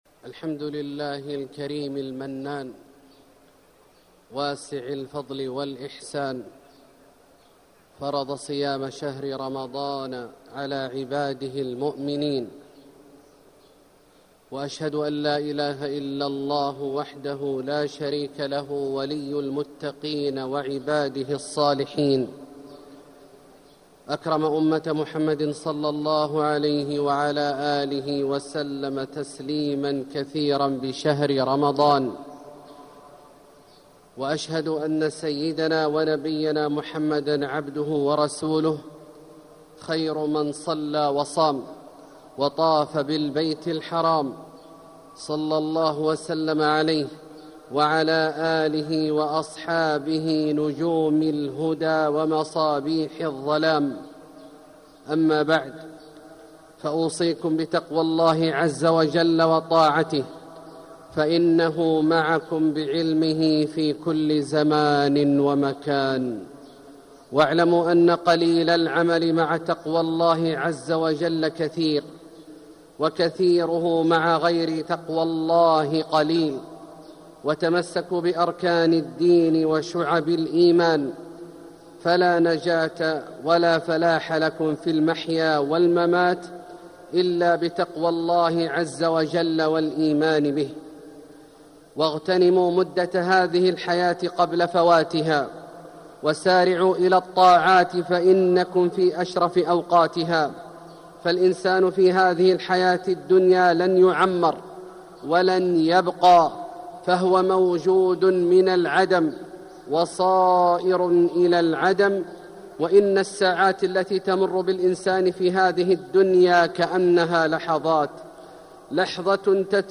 مكة: هدي النبي ﷺ في رمضان - عبد الله بن عواد الجهني (صوت - جودة عالية. التصنيف: خطب الجمعة